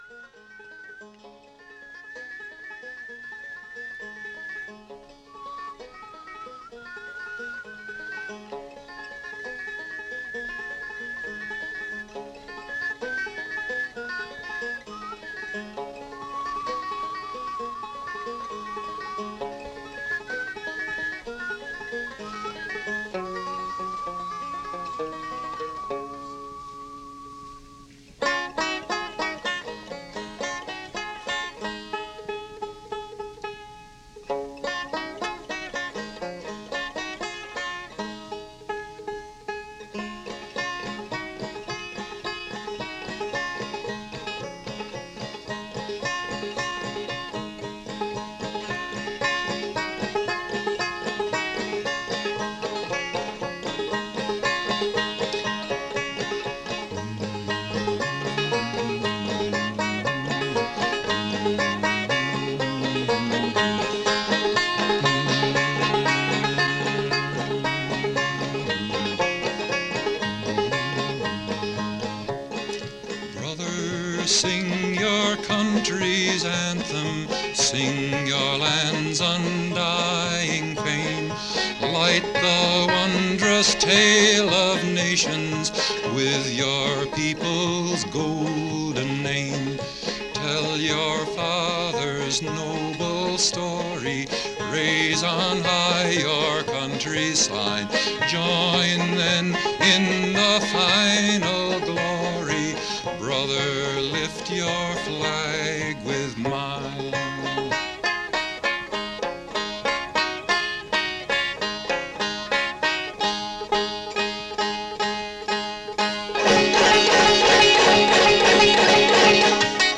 The Music is better – it’s not loaded with talk and high-pressure; it’s cool and doesn’t wind you up.
And the music is all over the place.
Bikel is hardcore Folk and music from other countries.